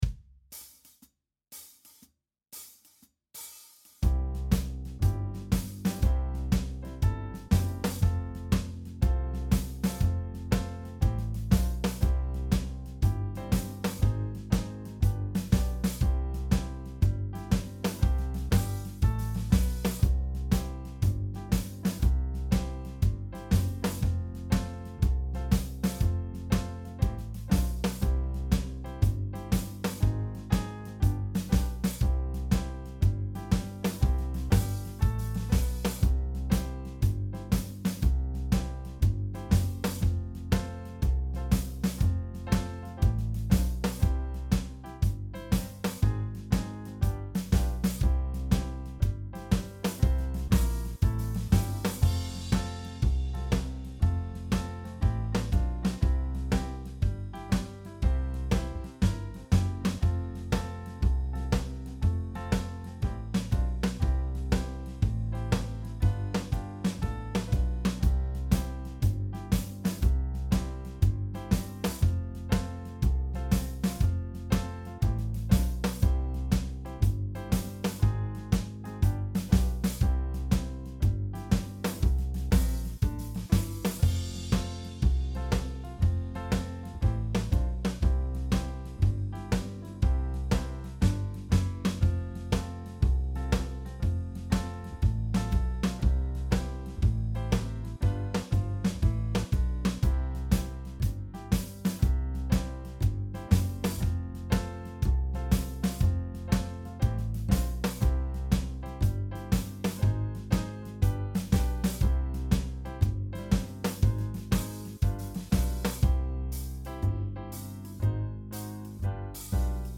Cover version